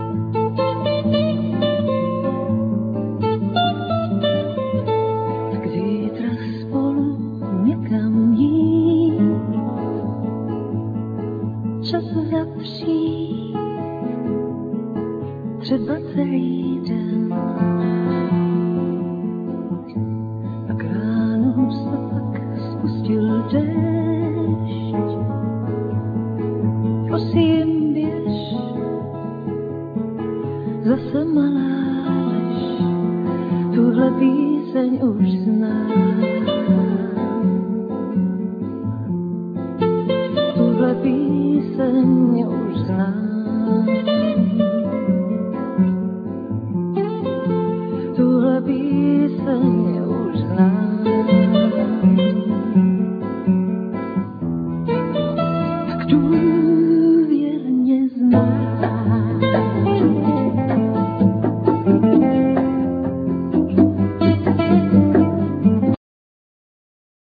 Vocal
El.+Ac.steel guitar
El.+Ac.nylon string guitar
Double-bass
Percussions
Piano